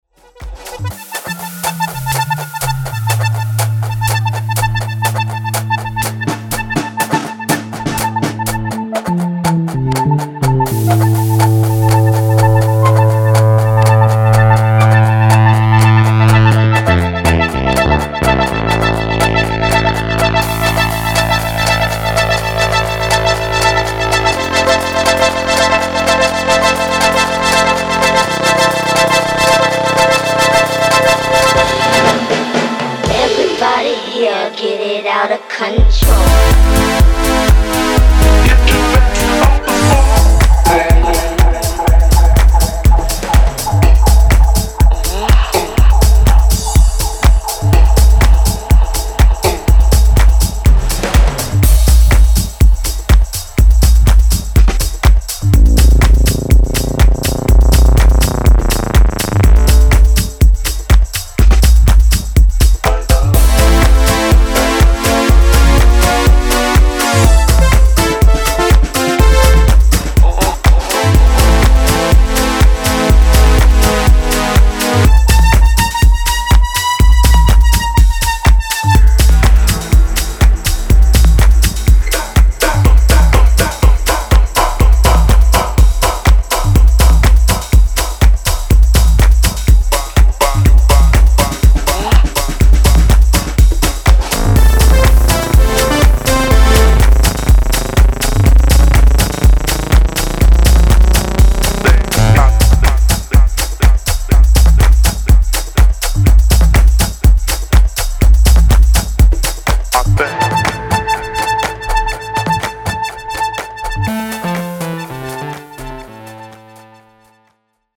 Style: Techno / Tech House